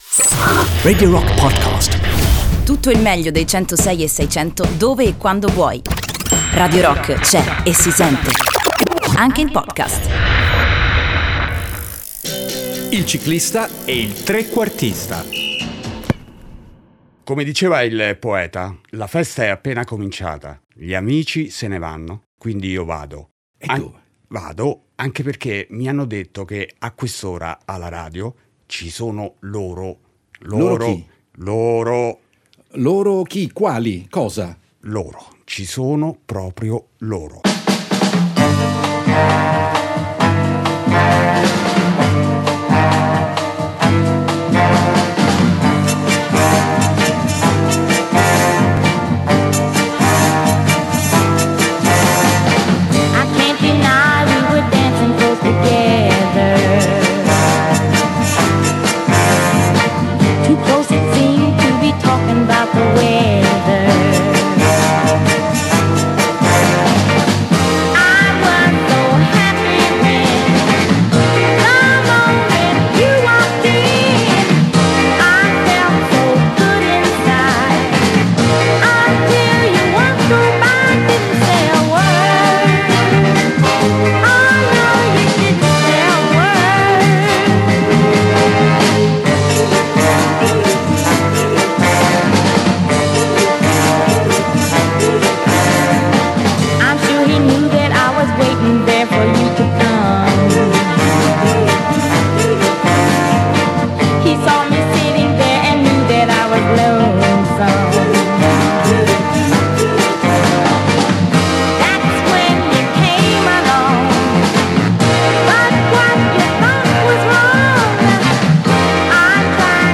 Un programma radiofonico di canzoni, storie e altre avventure più o meno improbabili.